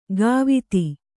♪ gāviti